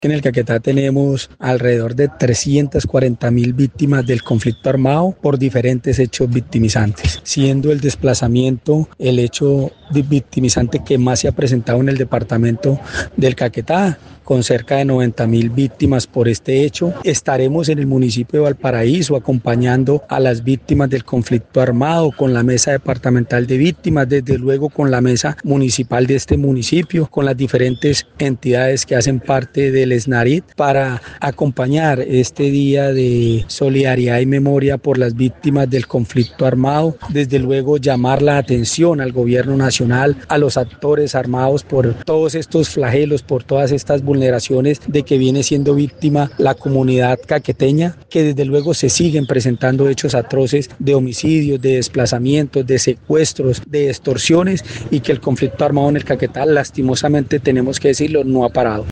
Edwin Leal Hernández, Defensor del Pueblo en Caquetá, dijo que, en el departamento el conflicto armado no ha parado.